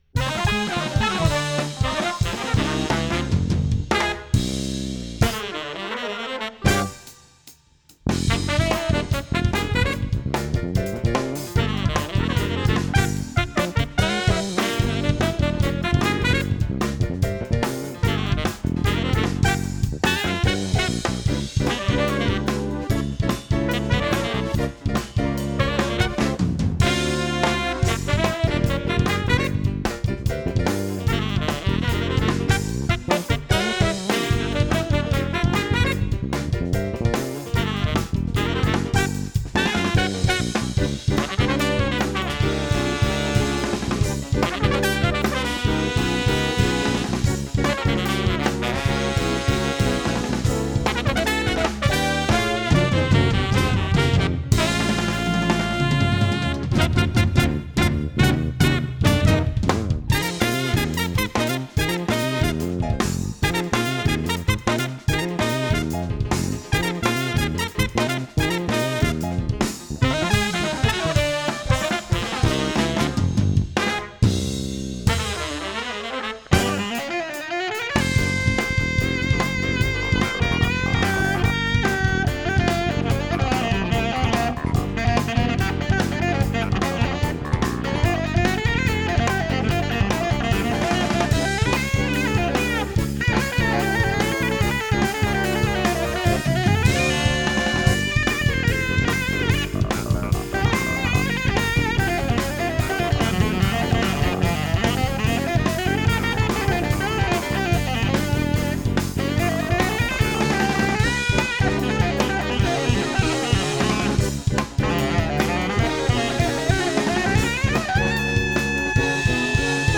Jazz
trumpet, fluegelhorn, and FX
tenor sax
electric bass
studio recordings